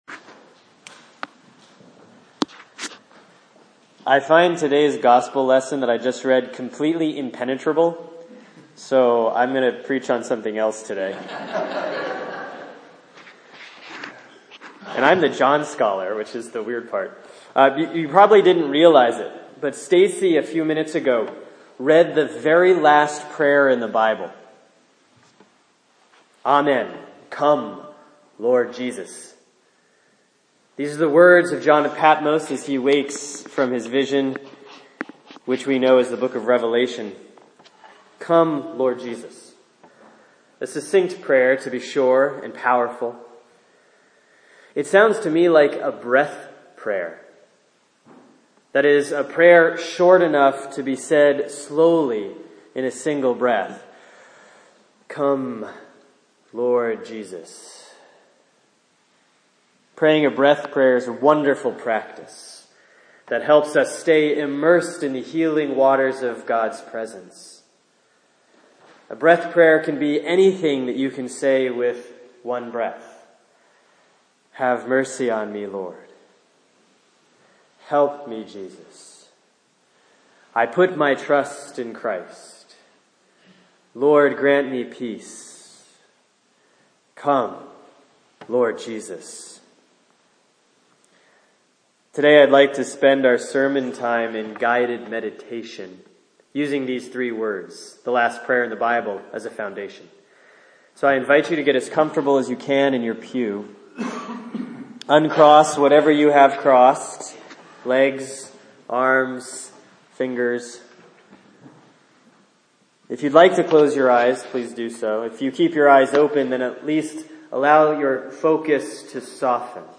Sermon for Sunday, May 8, 2016 || Easter 7C || Revelation 22